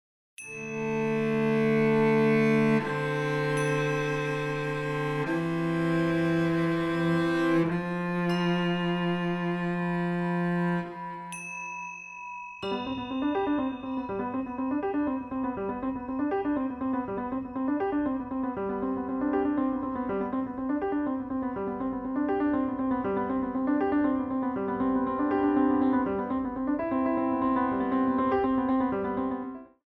Soprano Sax, Cello, Piano Percussion